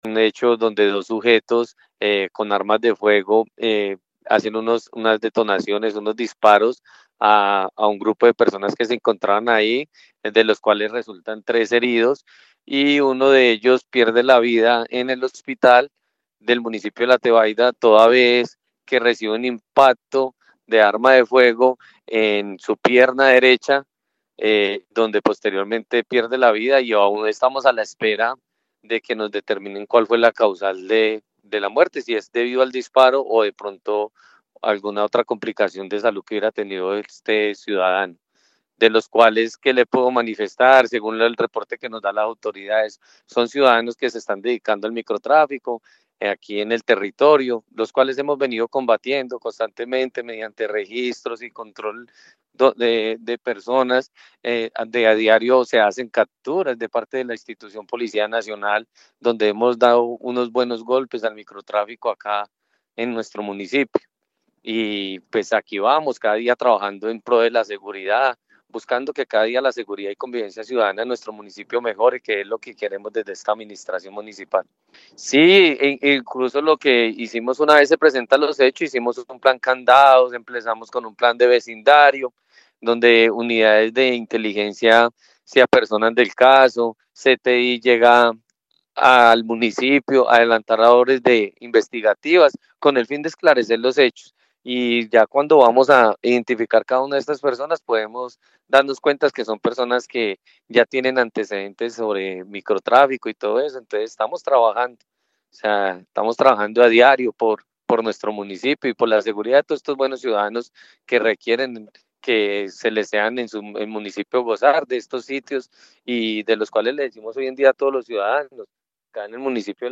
Secretario de Gobierno de La Tebaida